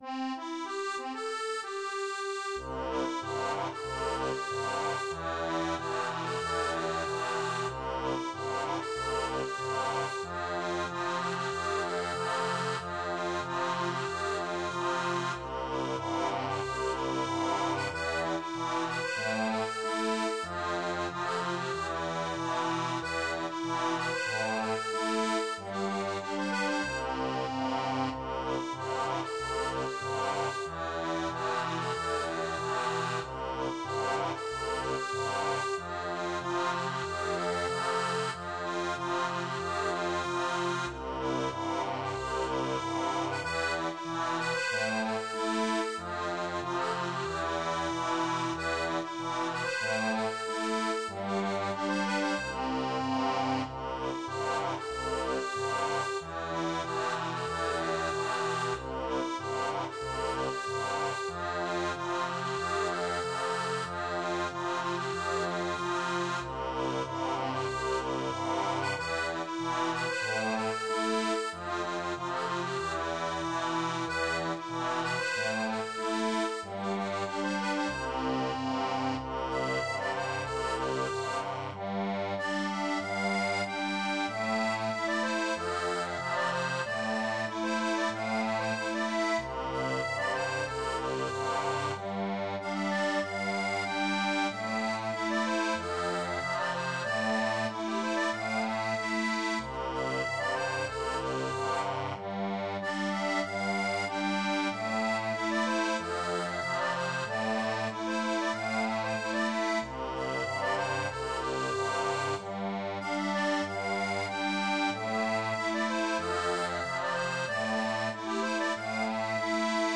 Folk et Traditionnel